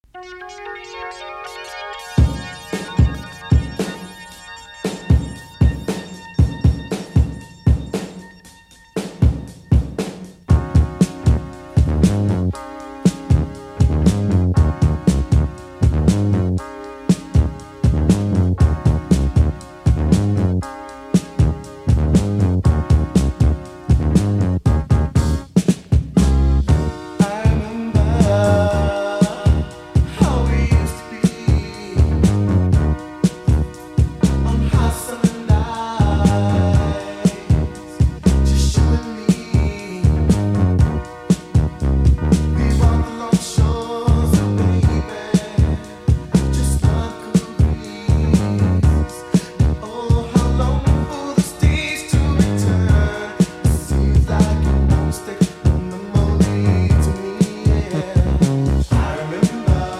New soul / boogie discovery out Daytona Beach, Florida.